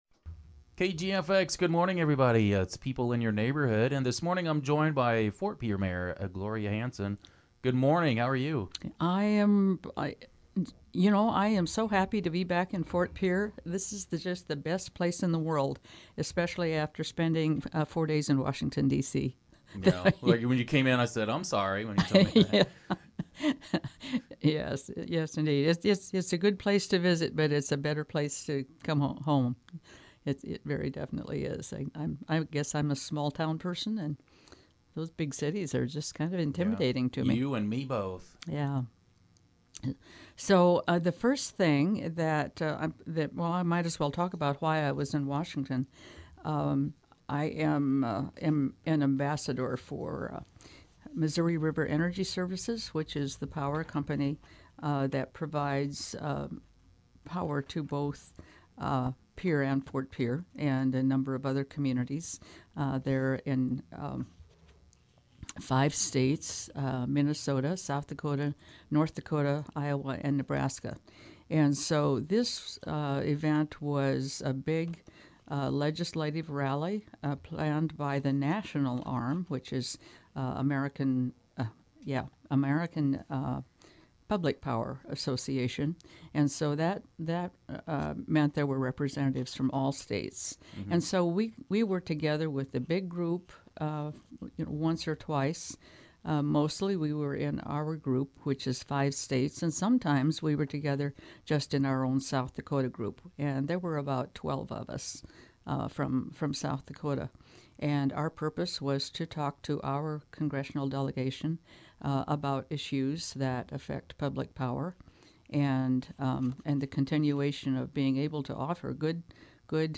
speaks with Ft Pierre Mayor Gloria Hanson